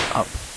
Recovered signal (NLMS)
• NLMS appears to be better in first case while RLS is better in second case.